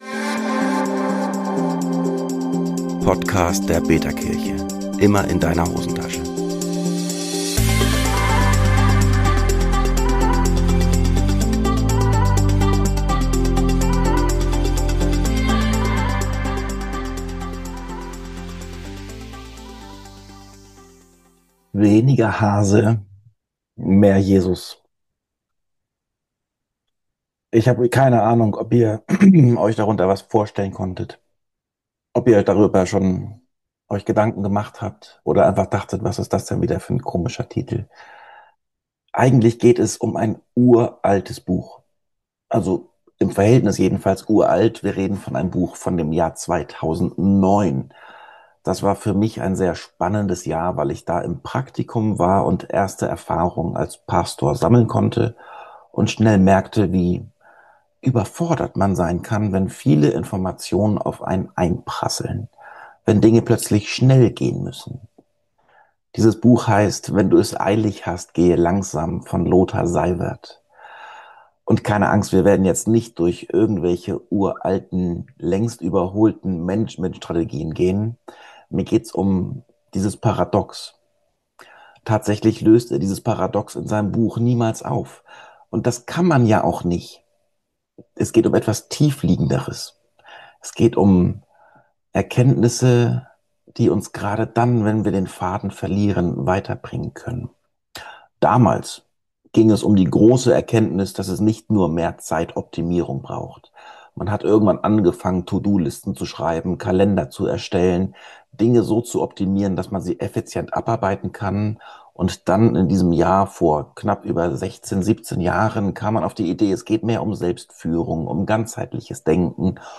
Feiere mit uns den Online-Gottesdienst der betaKirche im Februar 2026.